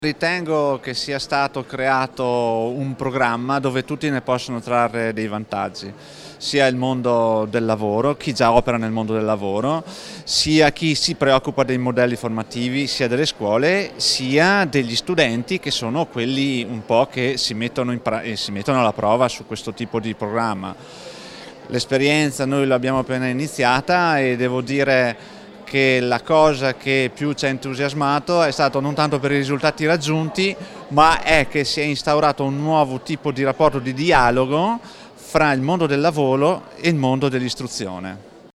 Ieri a Trento, nella Sala conferenze della Fondazione Caritro, presenti le parti sociali